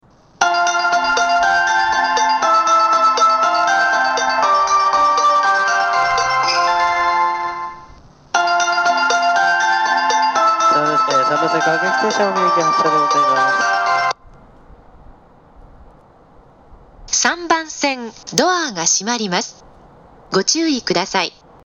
新宿駅3番線